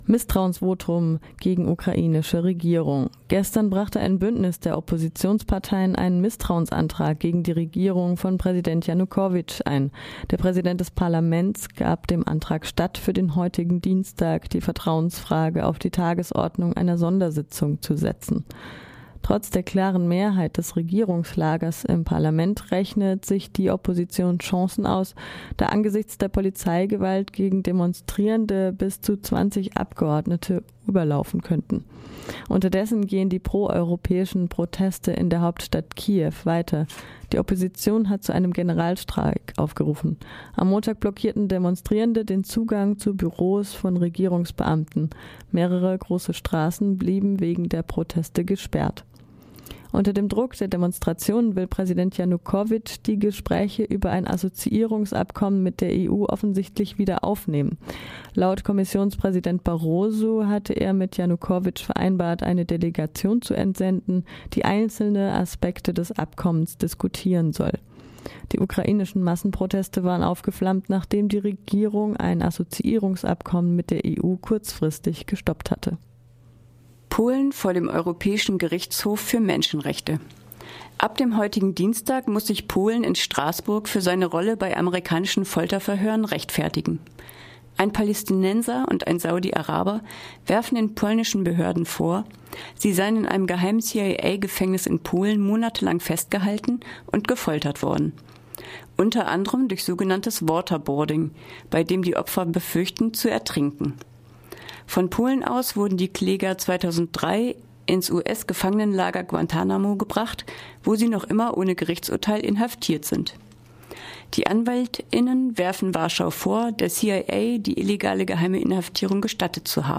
Focus Europa Nachrichten vom Dienstag, den 3. Dezember - 9.30 Uhr